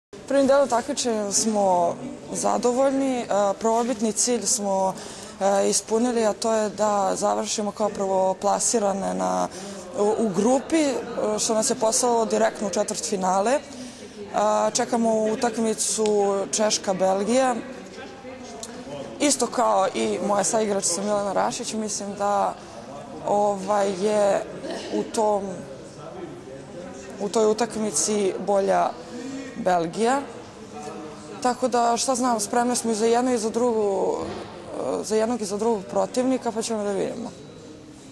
IZJAVA JOVANE STEVANOVIĆ